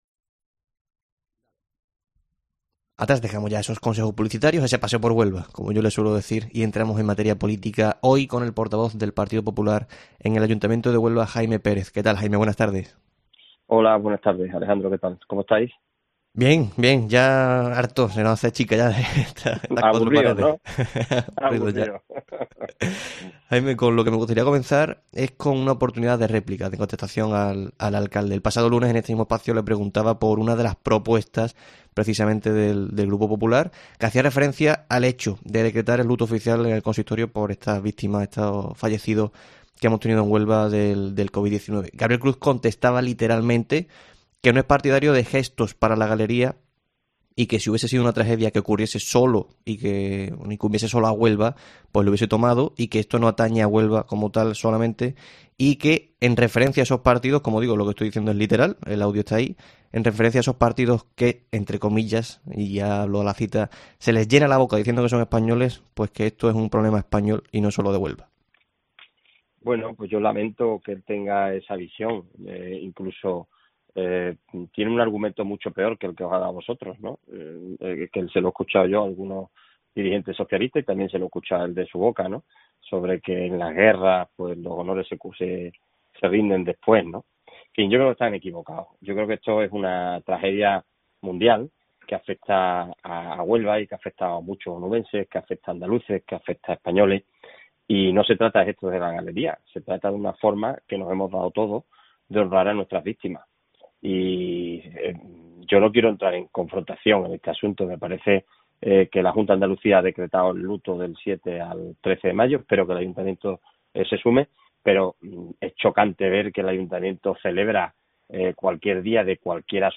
AUDIO: El portavoz del Partido Popular en el Ayuntamiento de Huelva, Jaime Pérez, expresa el malestar de la formación con la labor del gobierno local...